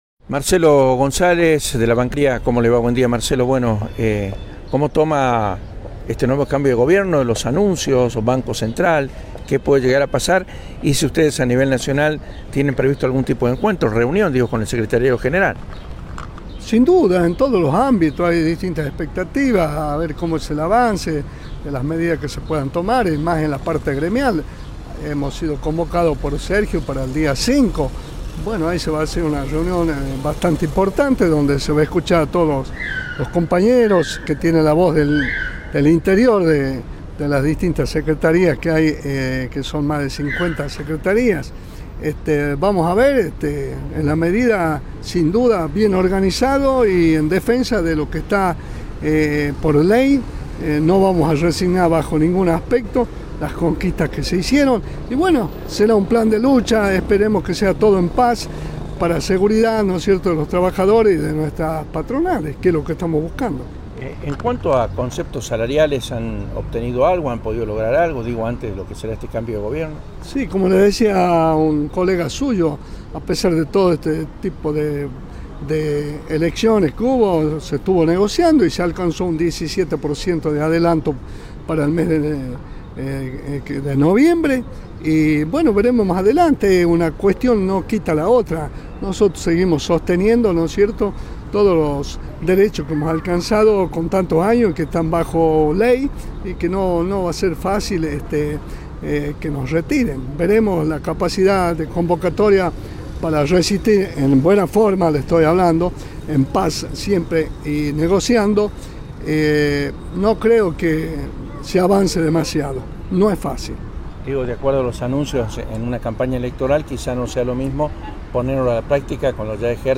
Entrevistas CityRadio CiTy EntrevistasVirales